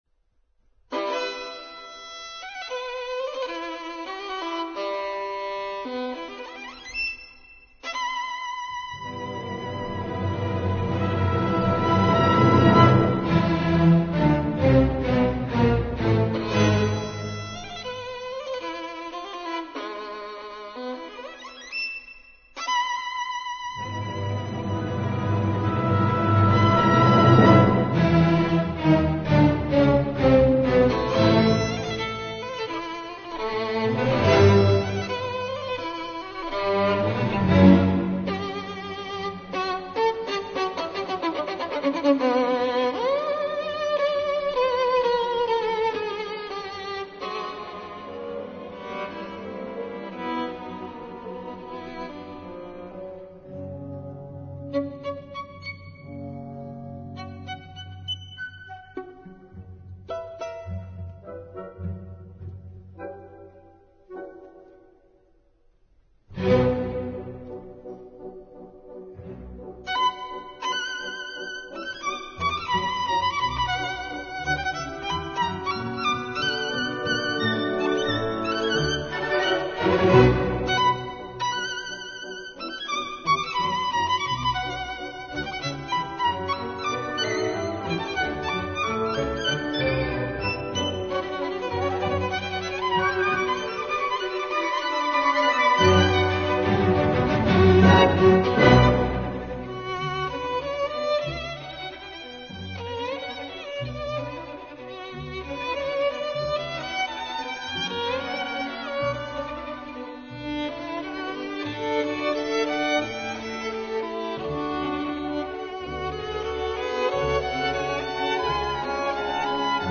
Concerto para piano No2